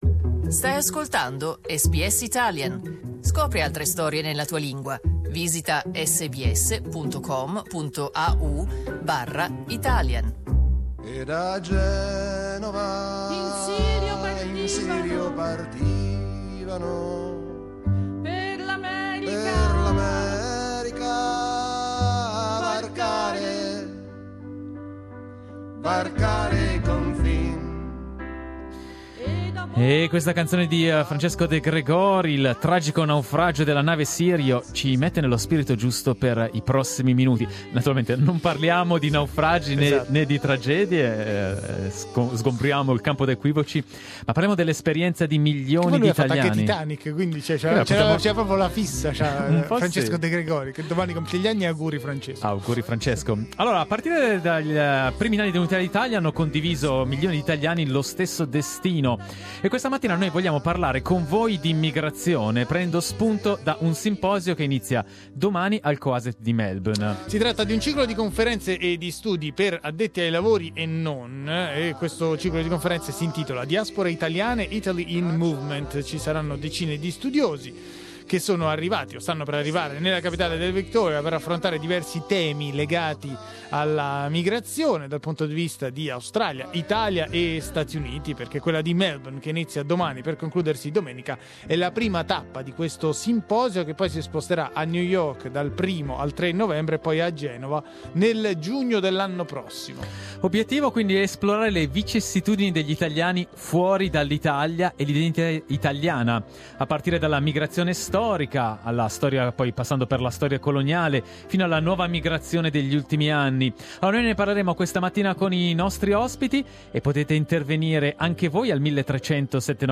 An international conference dedicated to Italian Diasporas is starting on April 4 in Melbourne. We talked to some of the guest speakers.